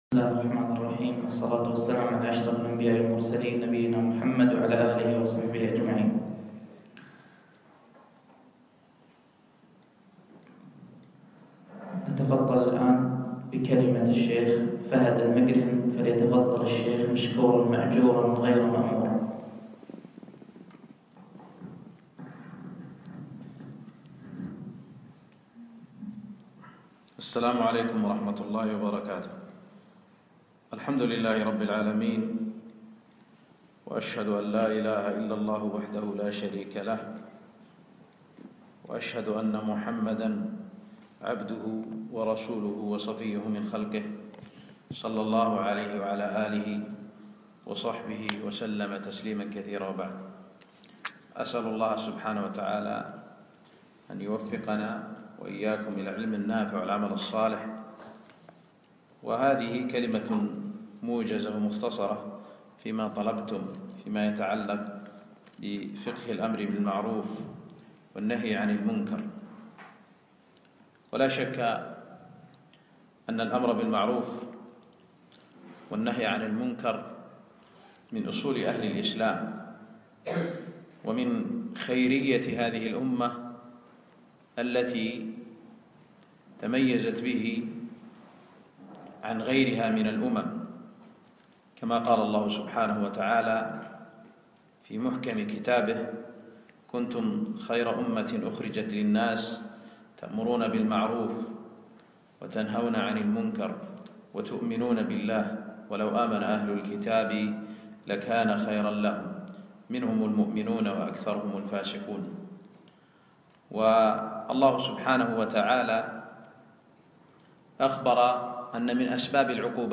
يوم الخميس 25 ربيع الأخر 1437هـ الموافق 4 2 2016م في مركز دار القرآن الفردوس نسائي الفردوس